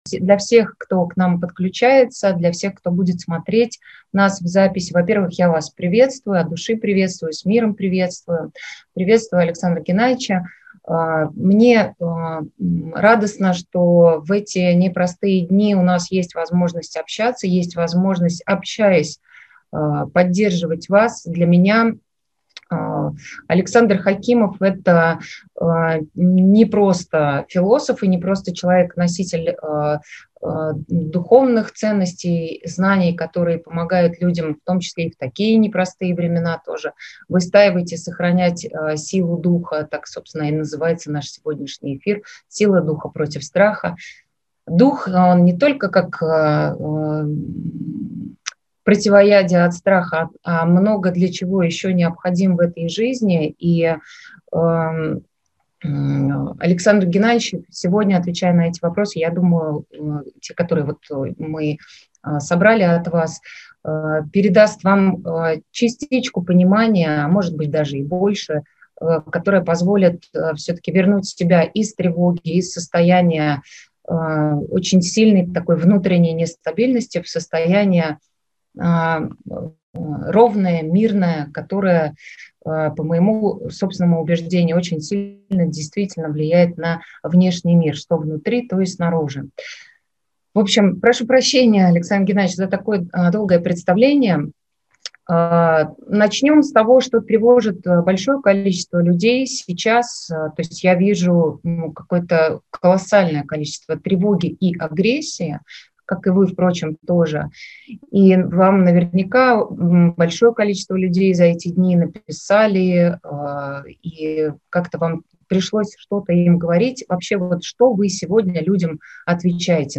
Сочи